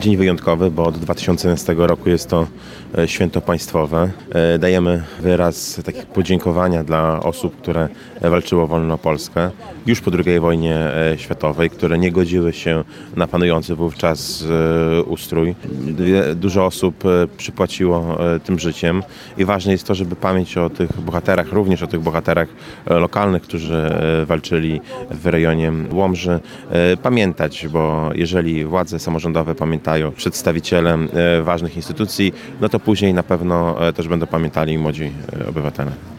Chcemy uczcić tych, którzy z odwagą i determinacją poświecili swoje życie walcząc o wolną Polskę – mówi prezydent miasta Mariusz Chrzanowski: